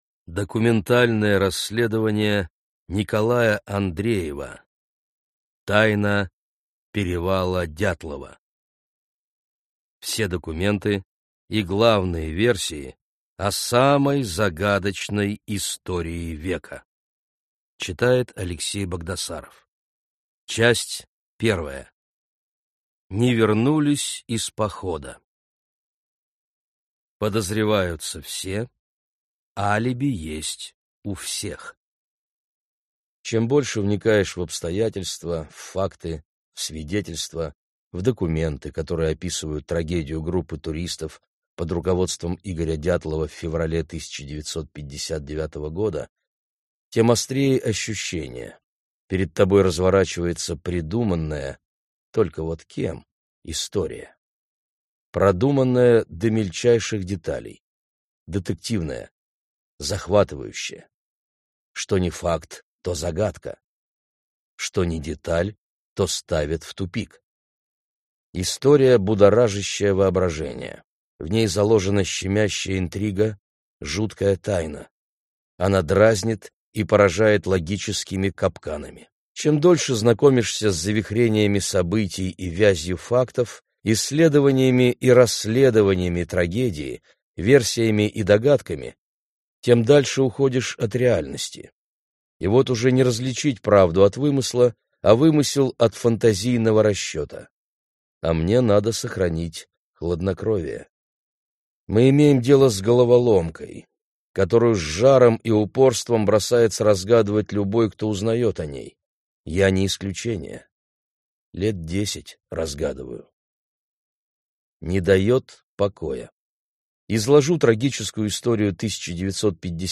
Аудиокнига Тайна перевала Дятлова. Часть 1. Не вернулись из похода…